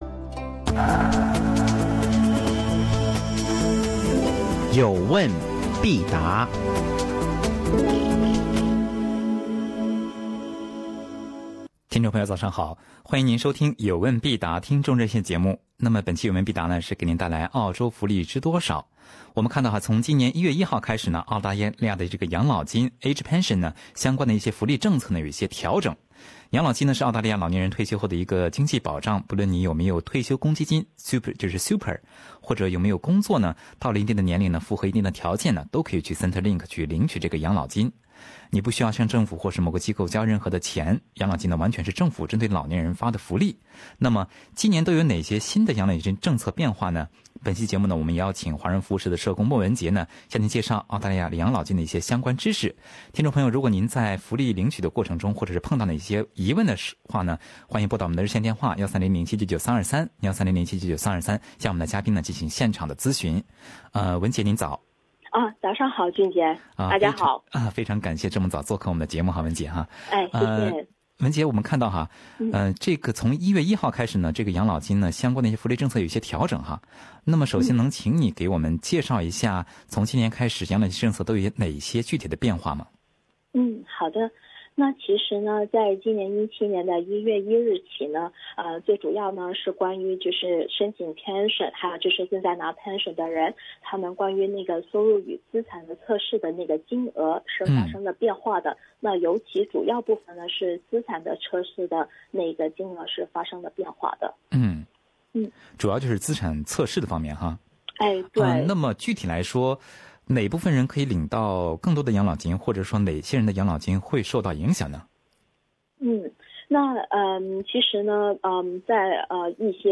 本期《澳洲福利知多少》听众热线节目